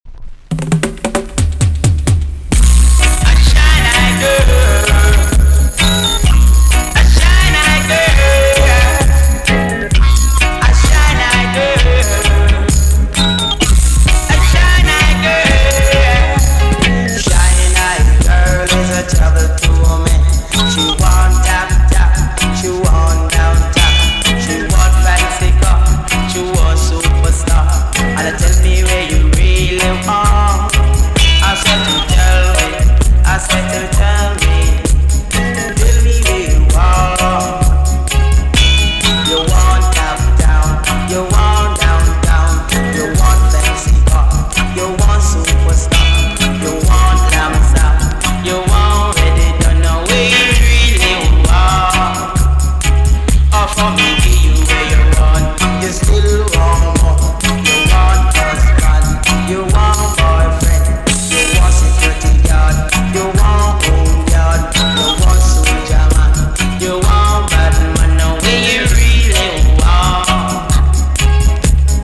TOP >LP >80'S 90'S DANCEHALL
A.SIDE EX 音はキレイです。